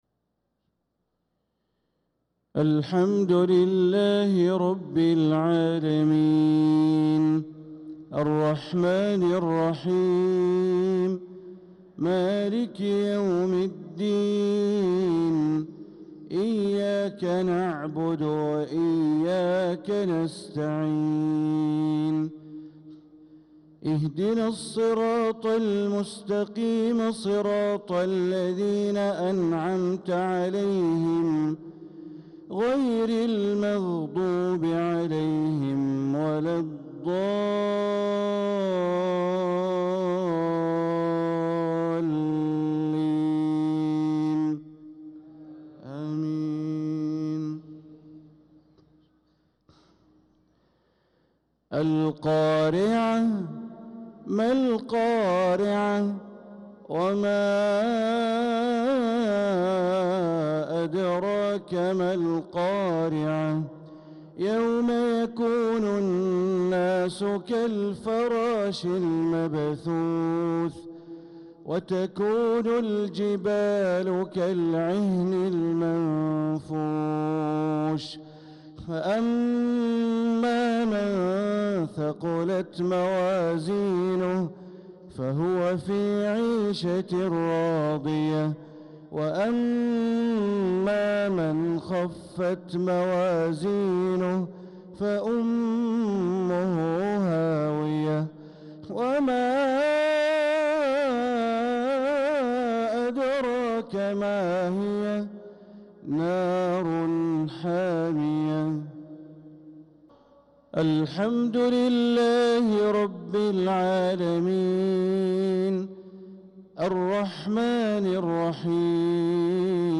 صلاة المغرب للقارئ بندر بليلة 11 ذو الحجة 1445 هـ